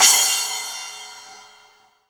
1SA CYMB.WAV